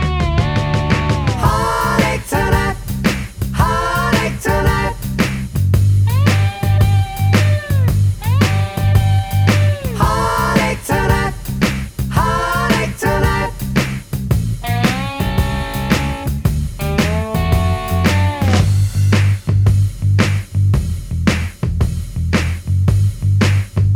Minus Slide Guitar Soft Rock 4:26 Buy £1.50